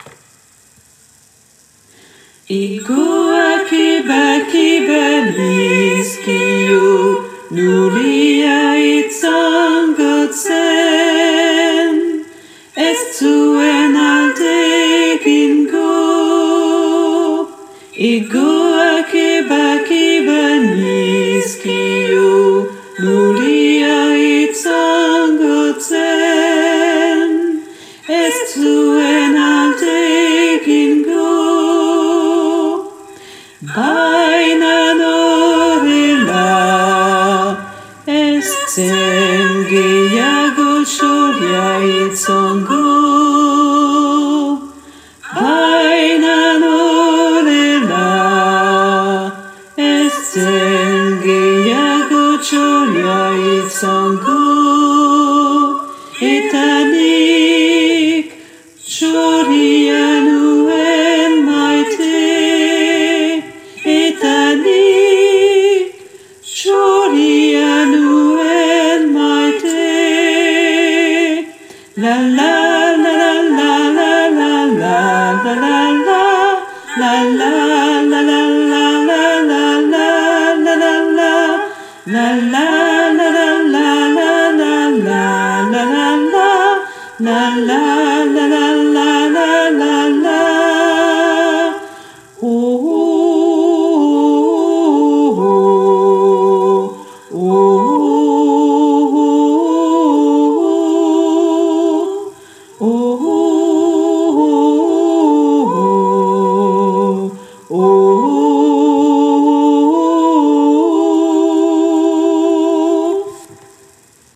- Chant pour choeur à 4 voix mixtes (SATB)
basse et autres voix en arrière-plan (version chantée)